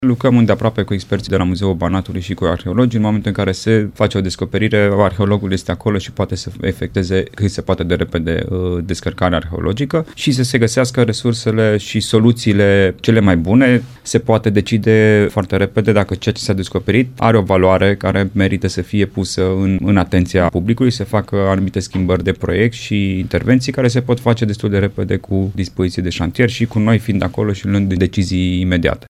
Săpăturile vor continua, iar specialiștii muzeului vor face descărcarea arheologică, spune vicepreședintele Consiliului Județean Timiș, Alexandru Iovescu.